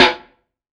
SNARE 032.wav